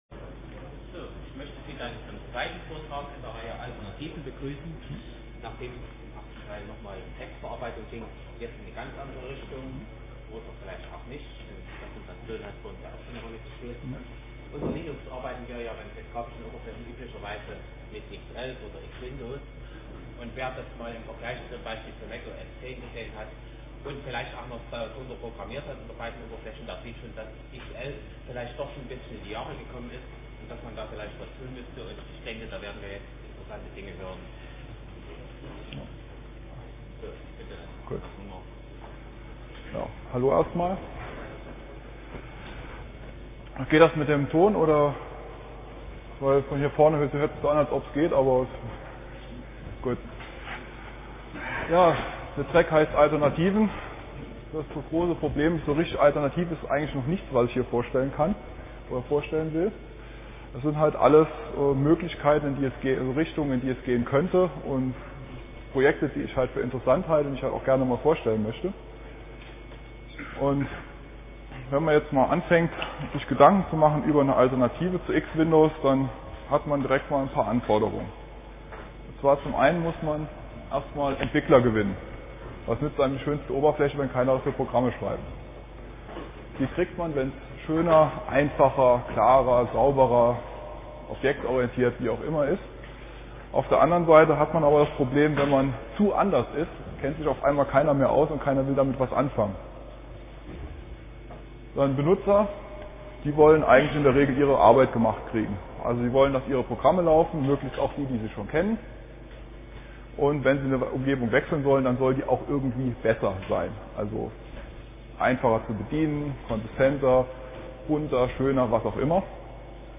Fresco & Co. Samstag, 15:00 Uhr im Raum V1 - Alternativen Desktop Linux muss sich mit Konkurrenten wie MacOSX und Windows XP messen lassen.
Vortragsmittschnitt